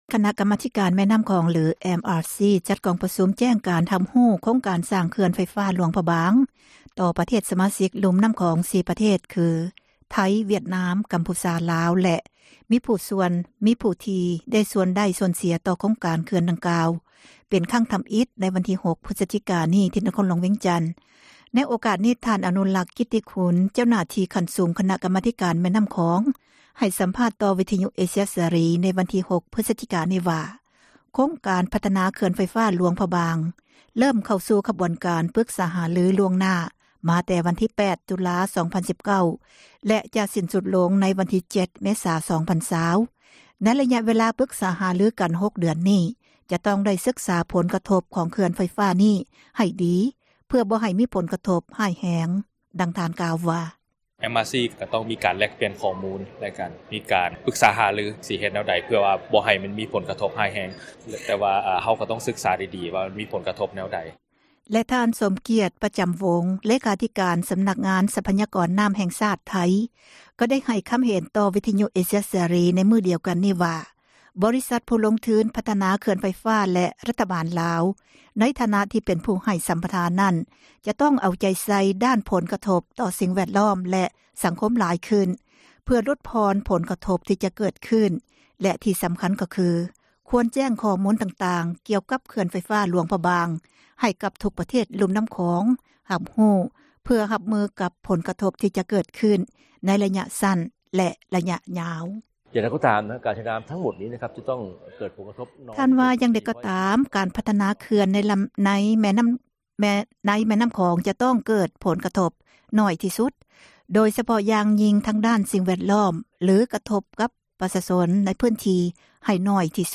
ໃຫ້ສັມພາດຕໍ່ ວິທຍຸ ເອເຊັຽ ເສຣີ